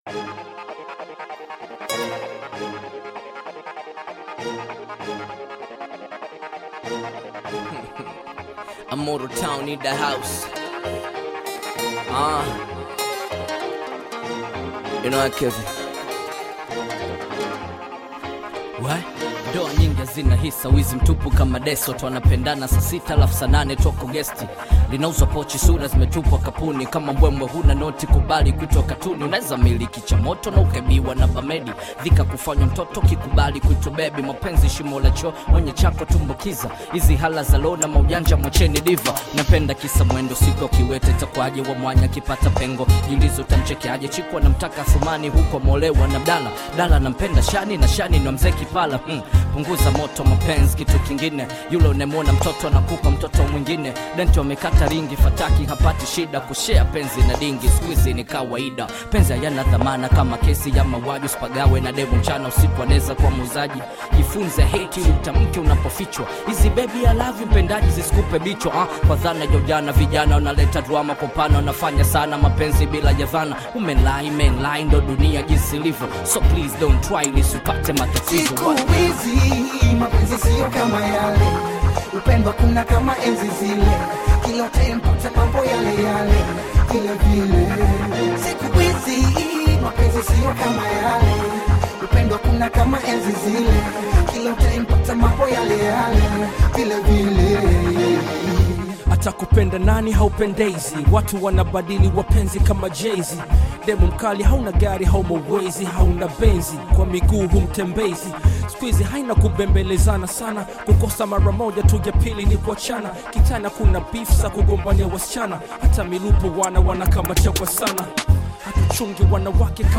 AudioBongo flevaHip Hop
is an energetic Tanzanian Hip Hop/Bongo Flava collaboration